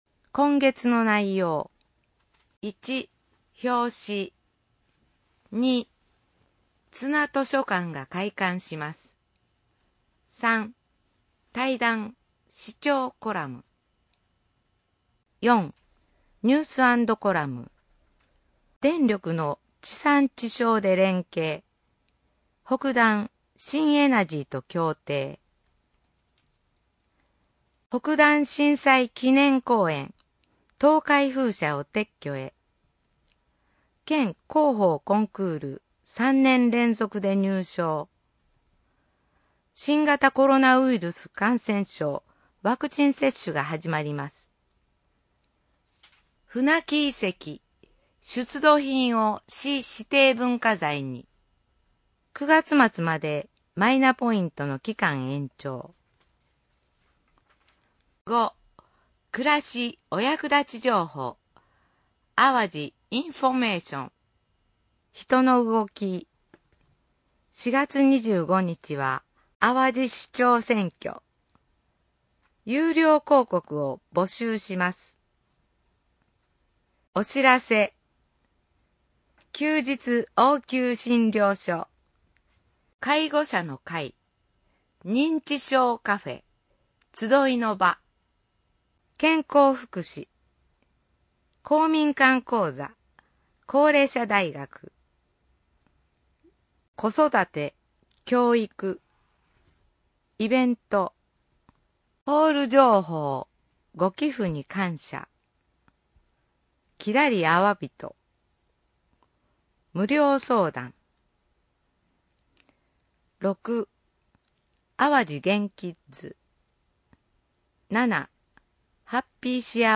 朗読　東浦朗読ボランティアグループ・ひとみの会